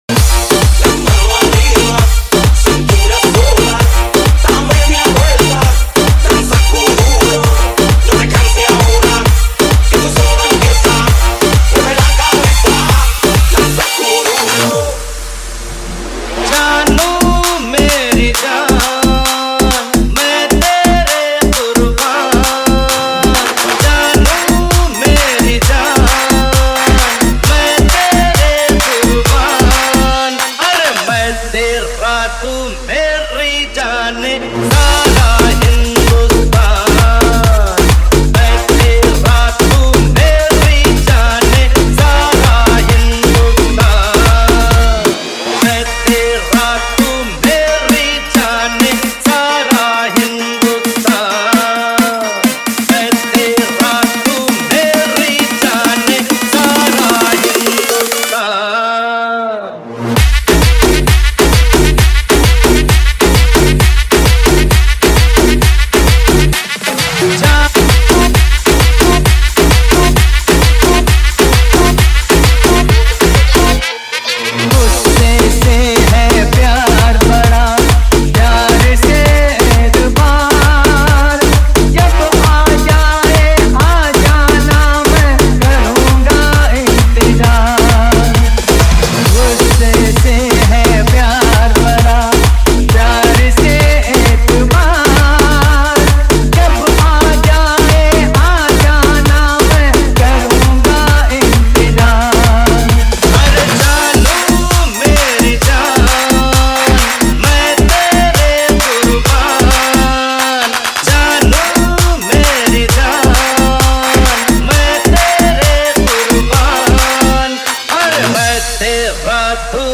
Bollywood Circuit House